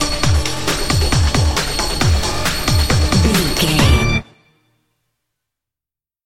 Aeolian/Minor
hard rock
lead guitar
bass
drums
aggressive
energetic
intense
powerful
nu metal
alternative metal